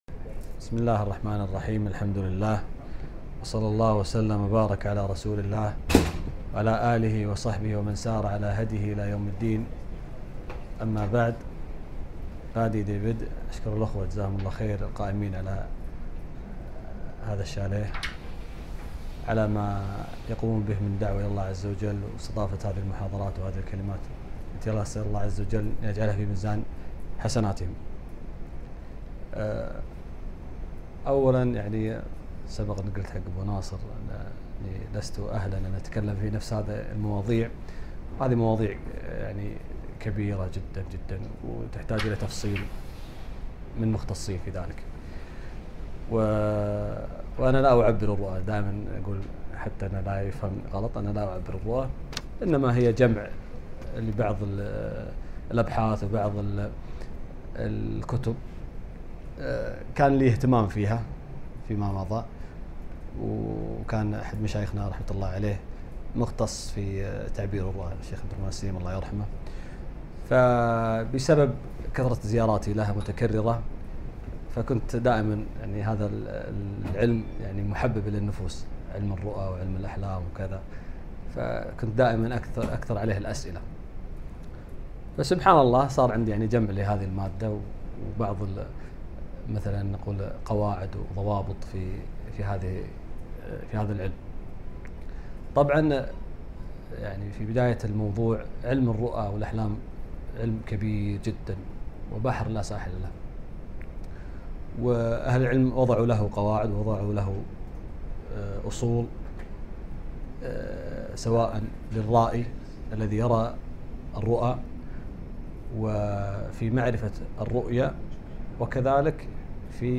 محاضرة - [ إشـارات على الــرُّؤى والأحـــلام ]